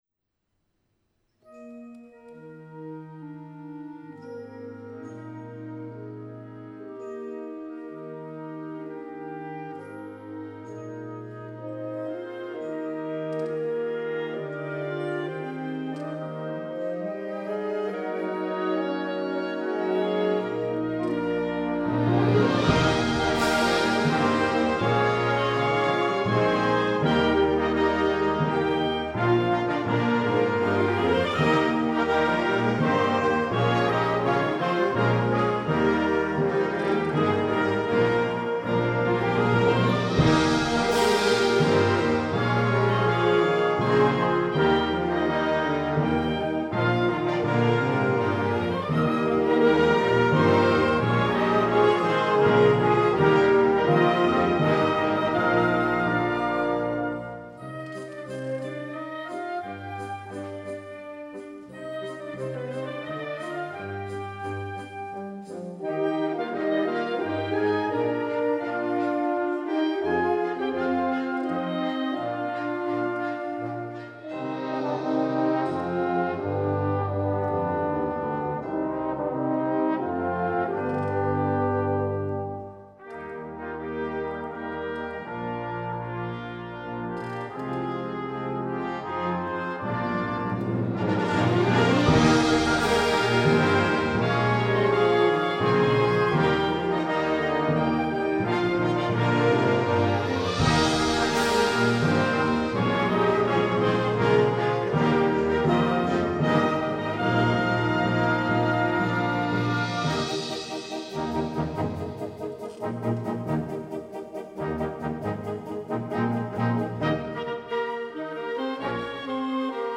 Gattung: Konzertwerk
8:30 Minuten Besetzung: Blasorchester PDF